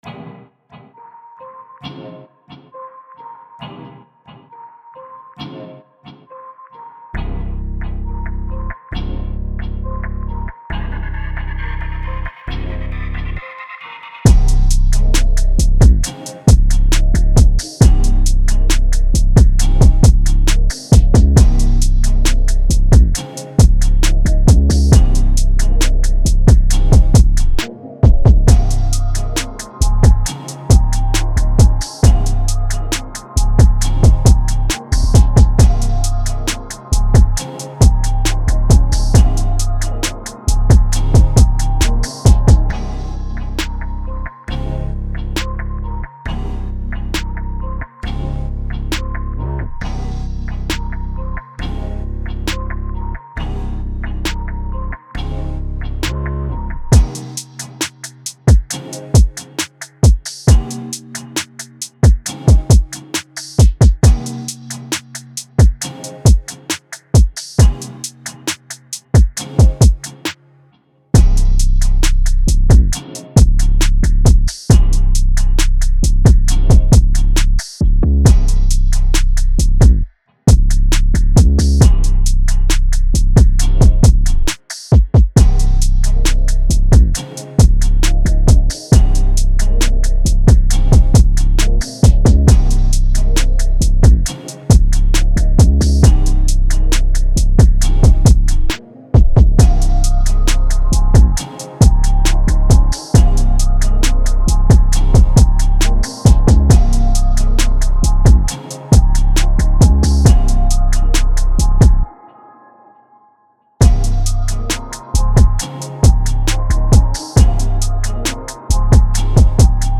R&B, Hip Hop
C Minor